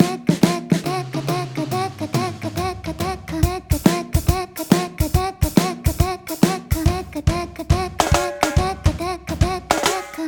3連符を「タカタ・タカタ」と表現するなら、3連中抜きは「タッカ・タッカ」という感じのリズム。
タッカタッカのリズム
スキップするようなこのリズム感は“跳ねた”リズムと称され、軽快な雰囲気やプリミティブなノリを表現するのに向いています。
r1-triplet-takkatakka-with-voice.mp3